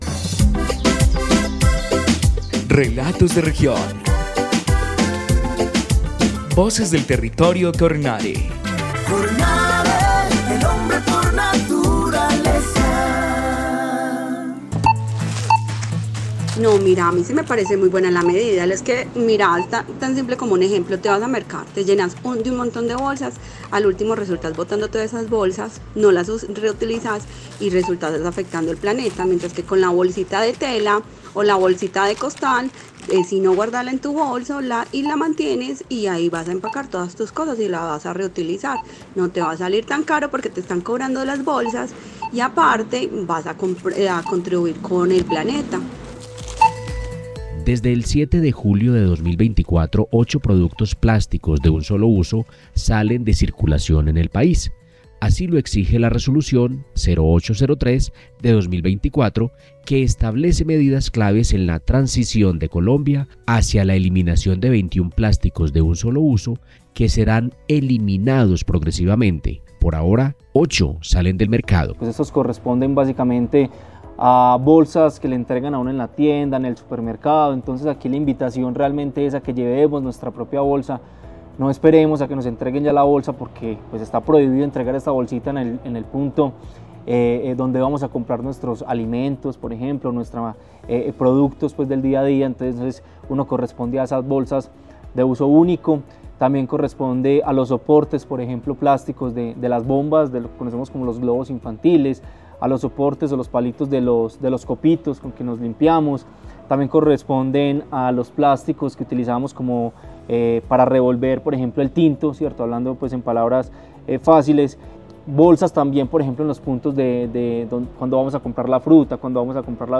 Programa de radio 2024